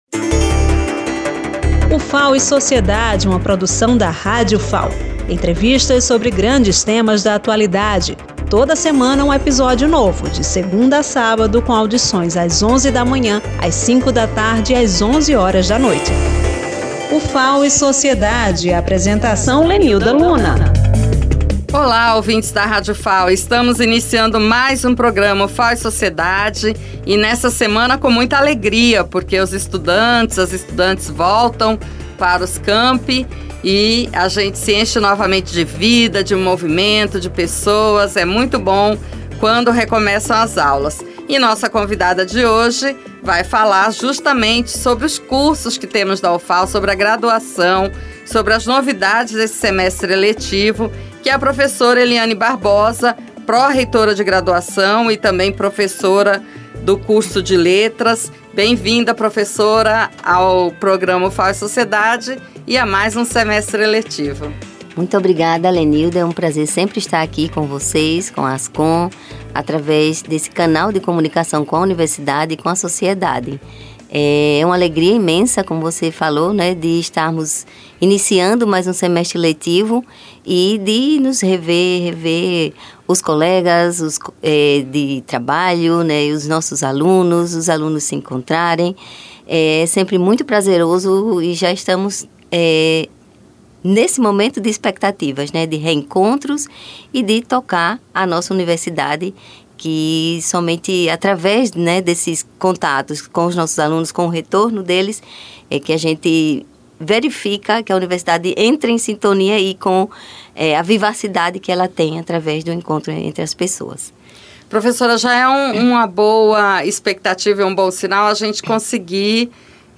A entrevista destaca a reorganização do calendário acadêmico em alinhamento com o calendário civil, garantindo maior previsibilidade e melhor organização das atividades para estudantes, professores e técnicos.